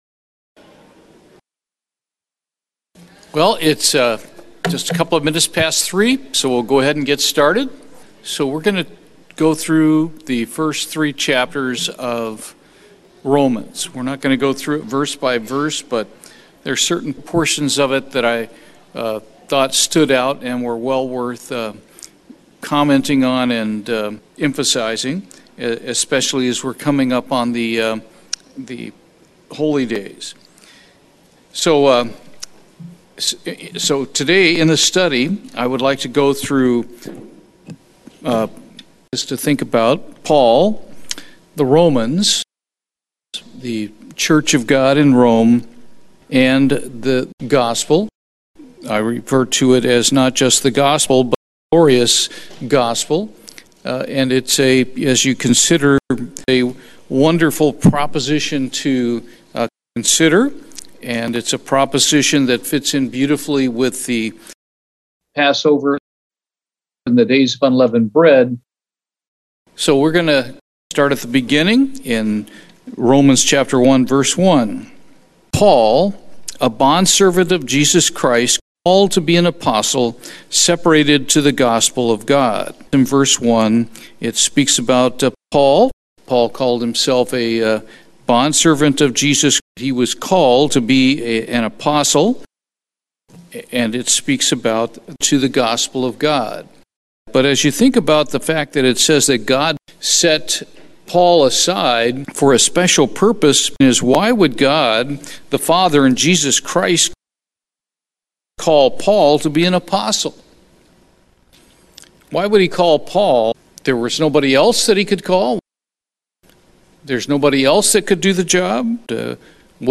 Bible Study - Paul, Romans, and the Gospel
Given in Houston, TX